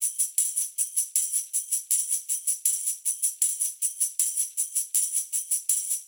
Index of /musicradar/sampled-funk-soul-samples/79bpm/Beats
SSF_TambProc2_79-03.wav